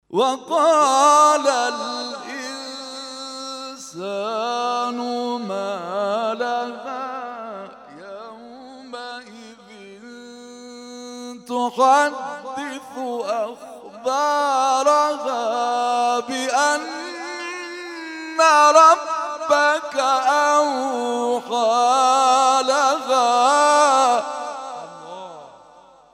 محفل انس با قرآن در آستان عبدالعظیم(ع) + صوت